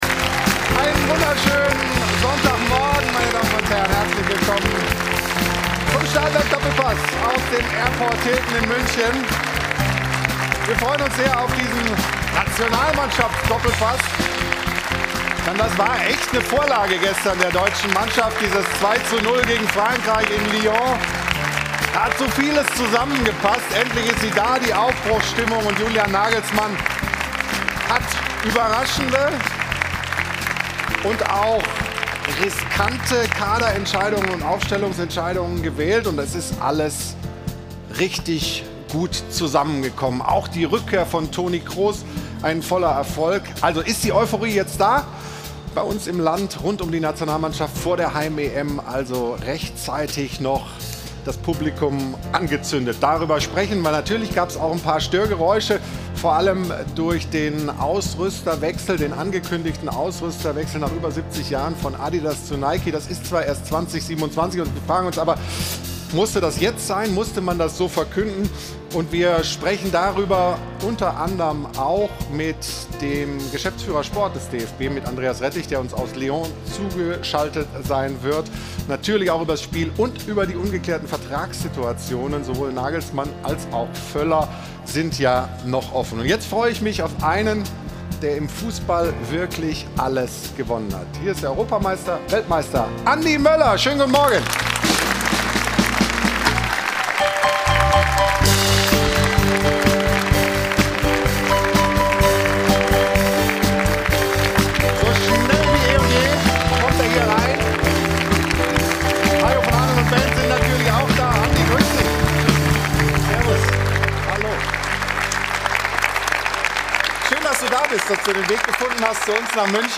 Zugeschaltet ist außerdem DFB-Geschäftsführer Sport Andreas Rettig. In der neuen Folge des Doppelpass diskutieren sie unter anderem über die Themen: 0:00 - DFB-Sieg in Frankreich: Grund zur Euphorie?